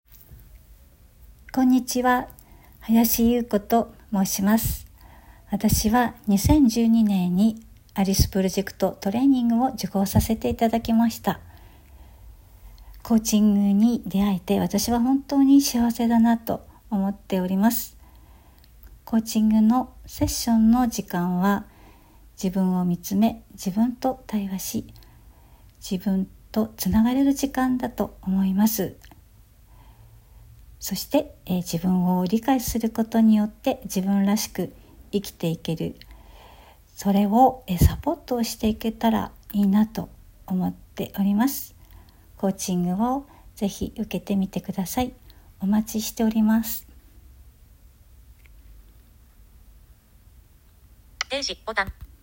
コーチからのメッセージ